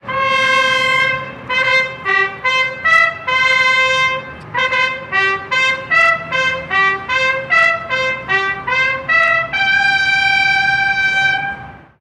Toque de corneta de relevo de guardia
aerófono
corneta
militar